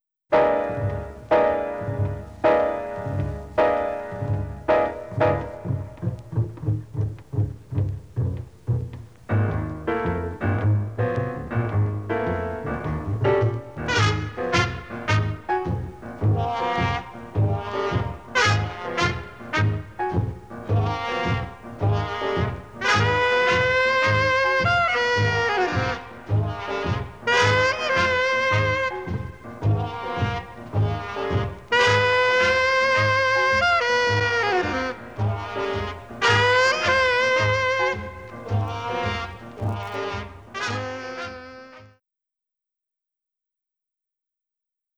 A big band composition
piano ostinato
It’s rough, dissonant, and also regular, orderly.